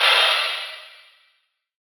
Gamer World Crash 4.wav